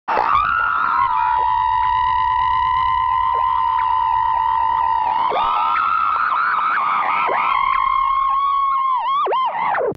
دانلود آهنگ رادیو 18 از افکت صوتی اشیاء
جلوه های صوتی